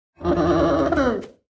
Minecraft Version Minecraft Version 1.21.5 Latest Release | Latest Snapshot 1.21.5 / assets / minecraft / sounds / mob / sniffer / happy4.ogg Compare With Compare With Latest Release | Latest Snapshot
happy4.ogg